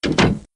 Added AIM sfx